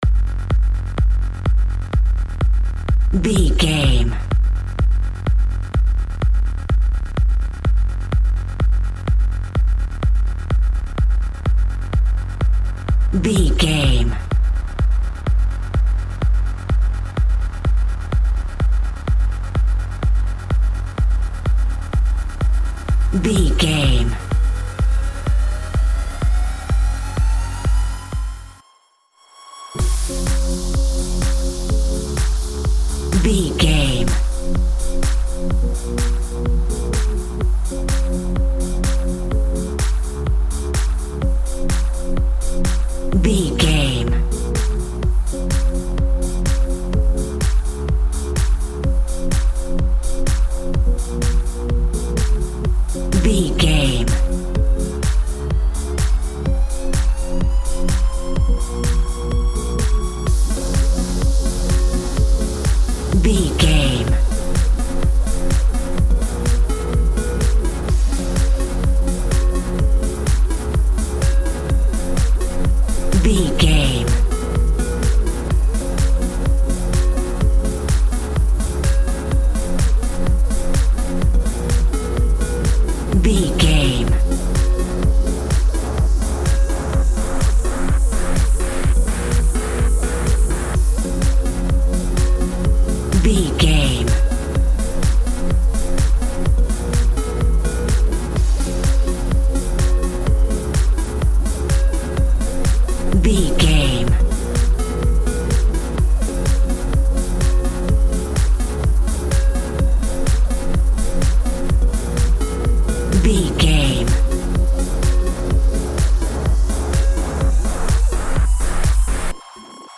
Aeolian/Minor
F#
groovy
dreamy
smooth
futuristic
drum machine
synthesiser
house
electro dance
synth bass
upbeat